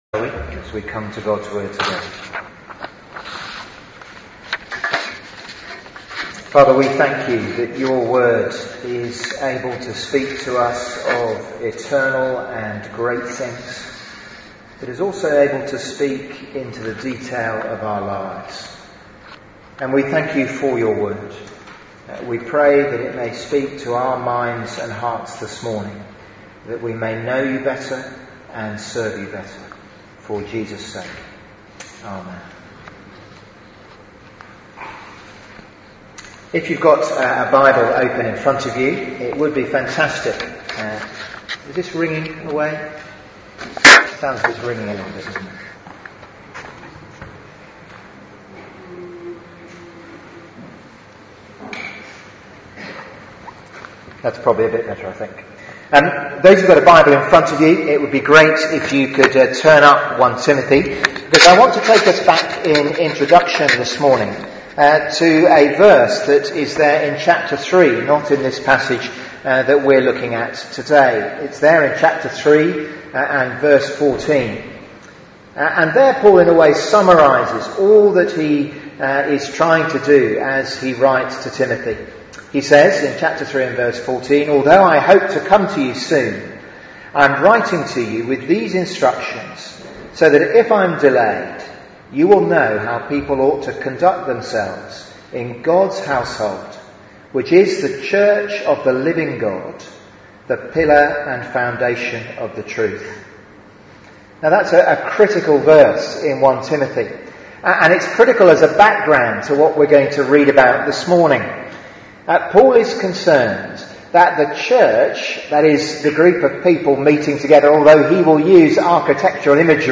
Media for 11am Service on Sun 13th Nov 2016
Theme: Living in the Church Sermon